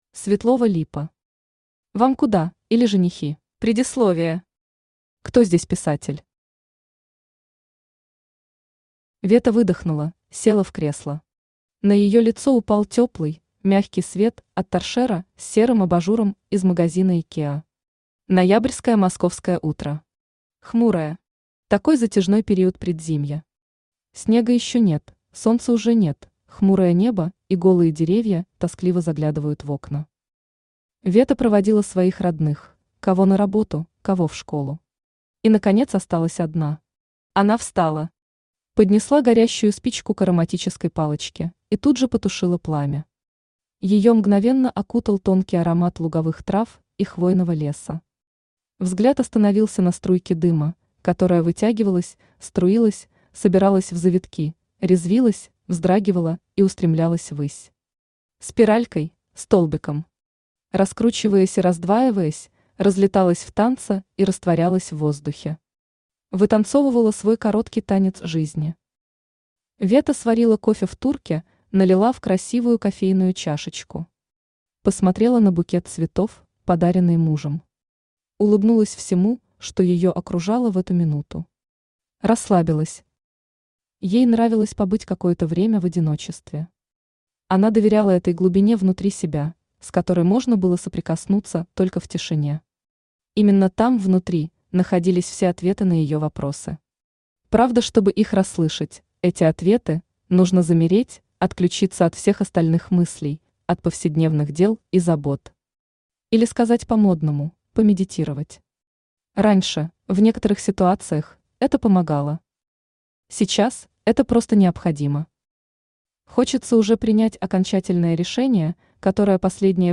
Aудиокнига Вам куда, или Женихи Автор Светлова Липа Читает аудиокнигу Авточтец ЛитРес.